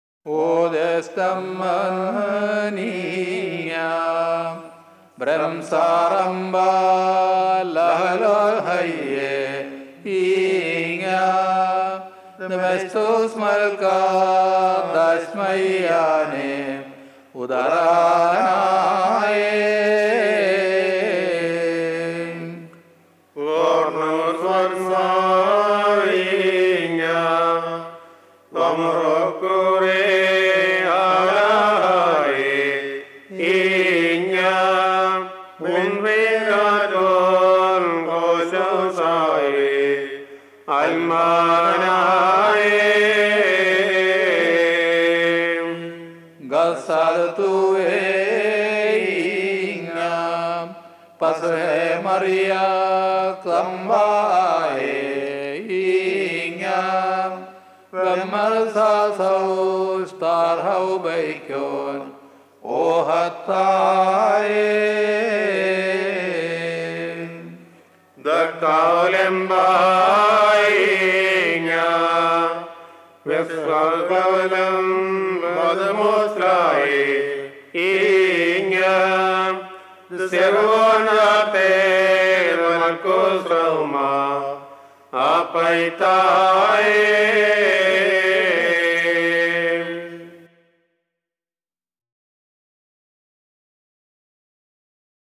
Triangle